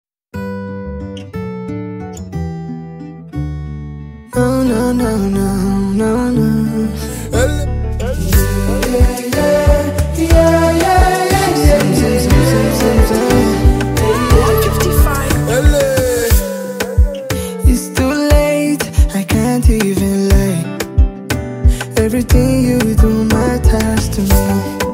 With its melodic harmonies and lyrical depth